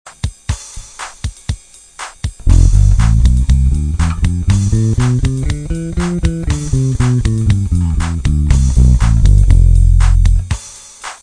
Cliquer sur Ex et vous  aurez un exemple sonore en F (Fa).
LYDIEN